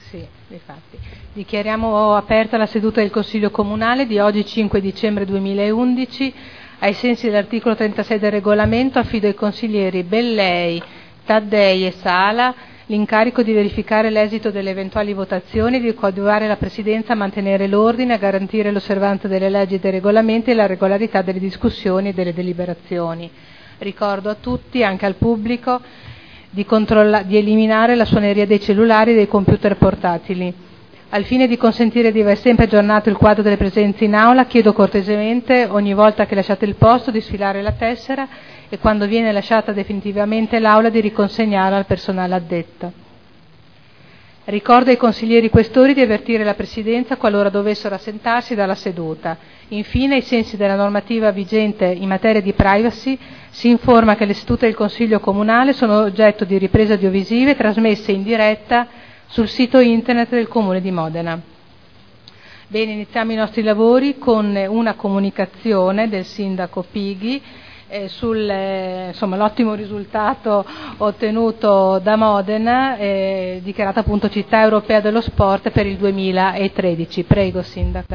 Il Presidente Caterina Liotti apre i lavori del Consiglio.